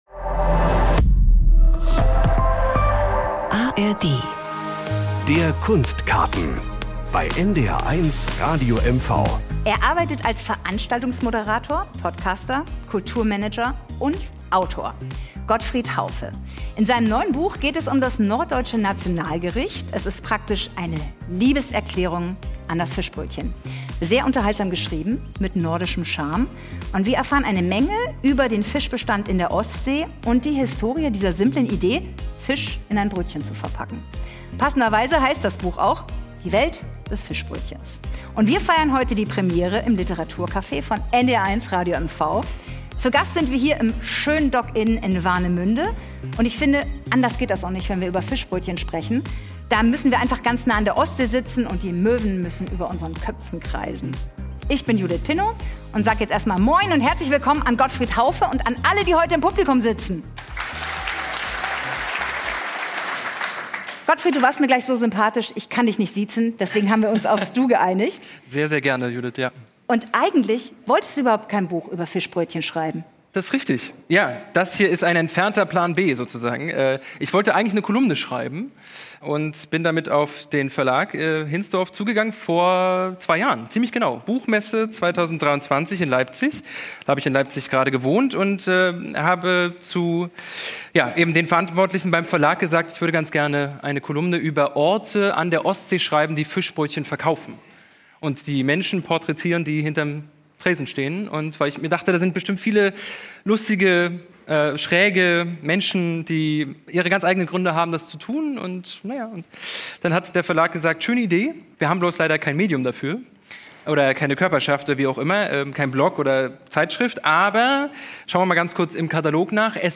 Es gibt Shantys zu Möwen und Fischbrötchen? Der Männer-Chor „Möwe und die Ölmützen“ brachte sie mit, nach Warnemünde, ins Hotel „Dock Inn“.